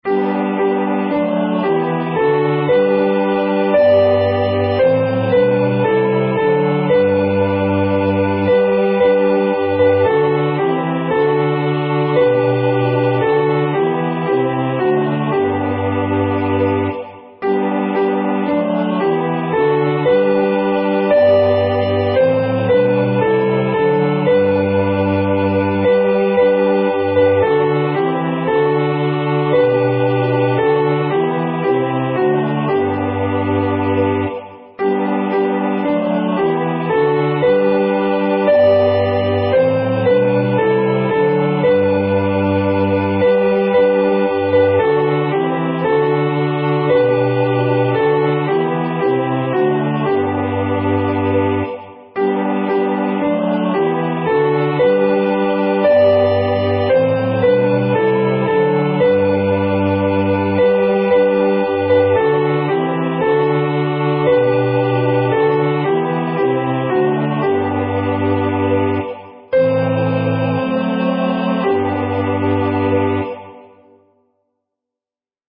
MP3 Practice Files: Soprano:
Genre: SacredMotet